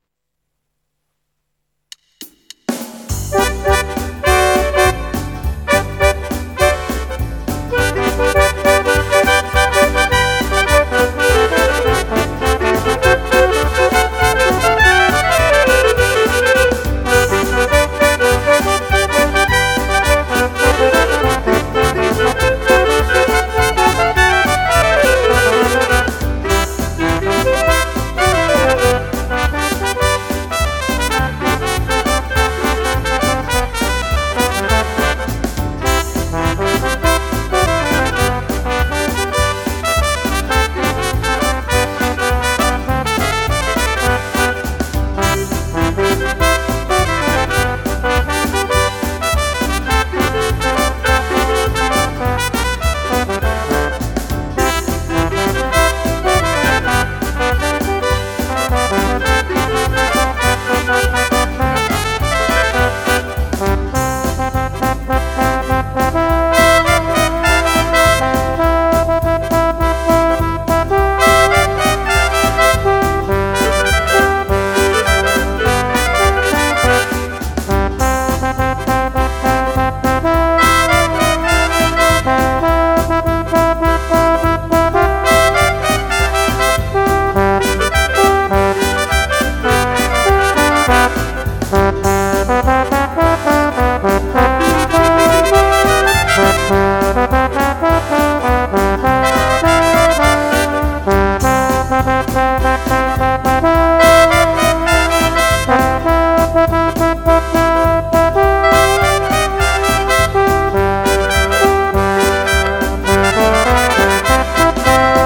Gattung: Marsch
Besetzung: Blasorchester
Der bekannte Marsch in einem modernen Arrangement
im Swing-Rhythmus
Schlagzeug